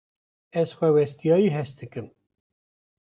/hɛst/